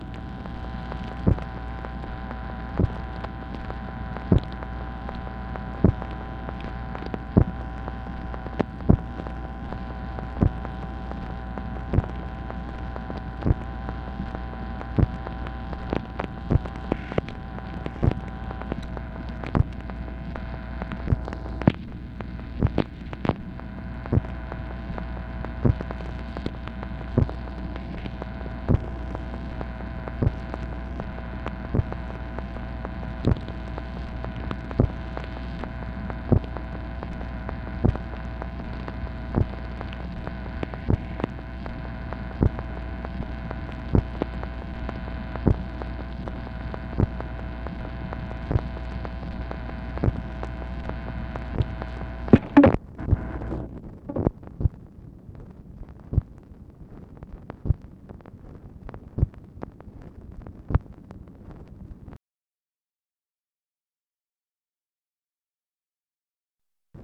MACHINE NOISE, August 29, 1966
Secret White House Tapes | Lyndon B. Johnson Presidency